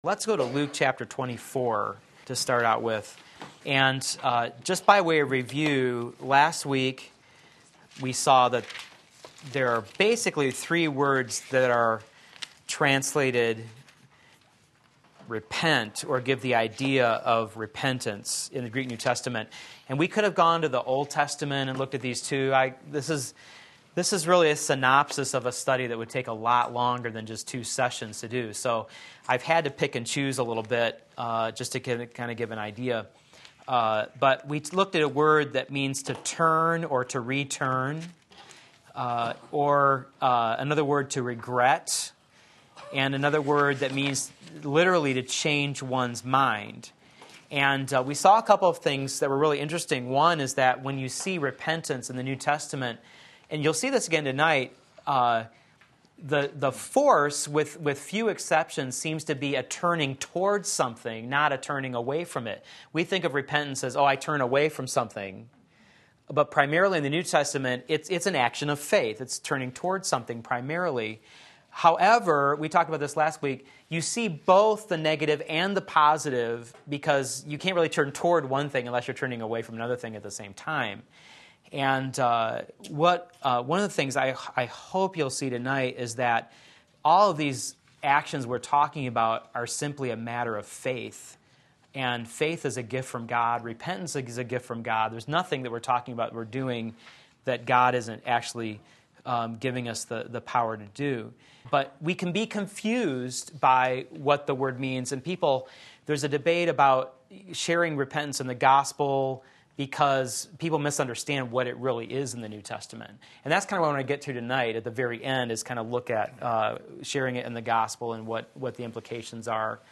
Sermon Link
Wednesday Evening Service